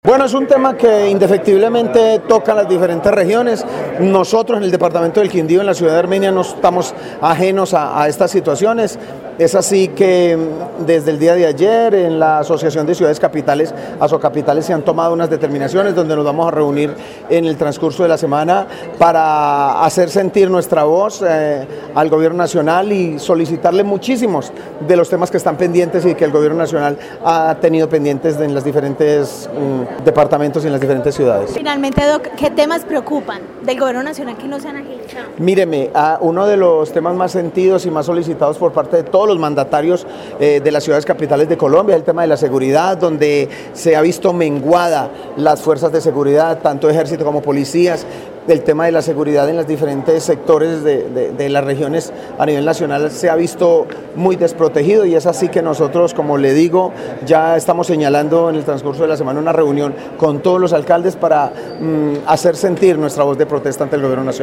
Alcalde de Armenia, James Padilla